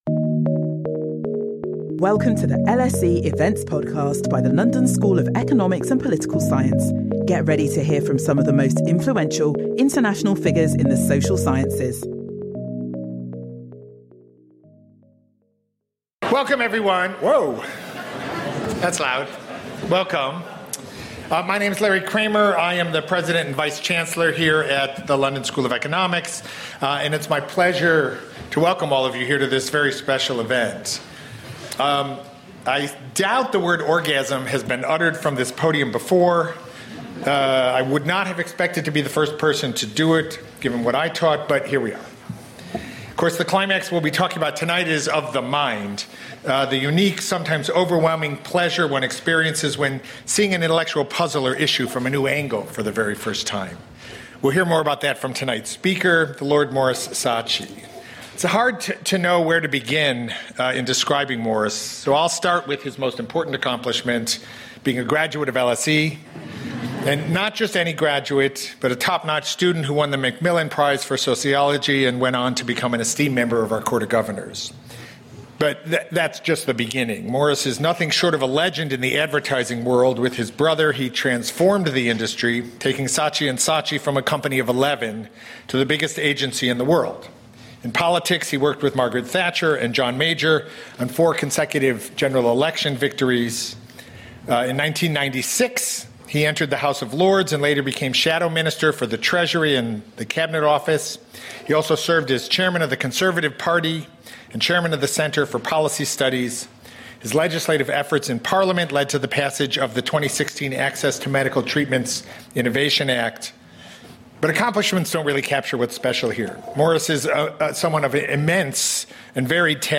In conversation with Maurice Saatchi
In an age of conformists and faux-contrarians, Maurice Saatchi has revolutionised British business and politics through his willingness to question received wisdom. He discusses with Larry Kramer his new book Orgasm, a vivid and engaging blend of memoir, philosophy and critical thinking, in which he debunks some of the modern world’s most widely-held social and cultural delusions, in his inimitably witty and pugnacious style.